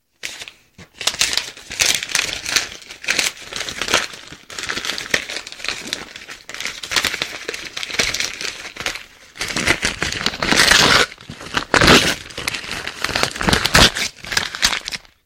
Звуки на звонок